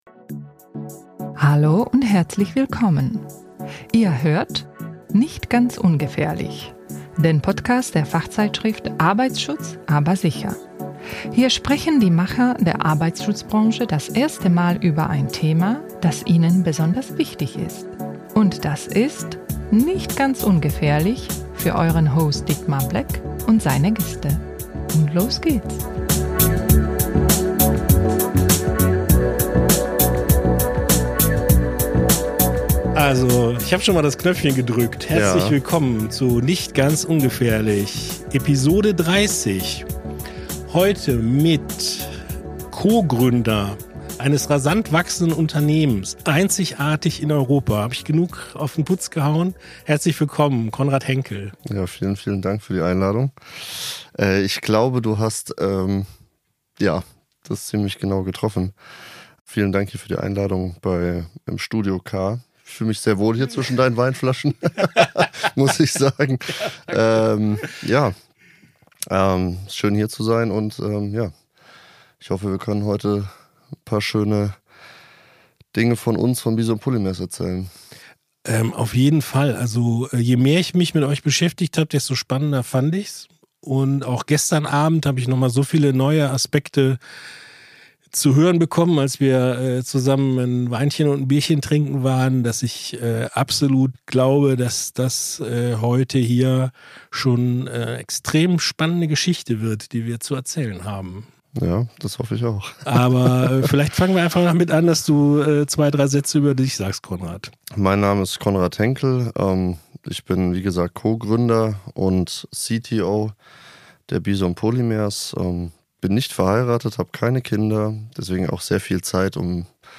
Ein lockeres Gespräch über wahren Pioniergeist und das Unternehmertum im 21. Jahrhundert.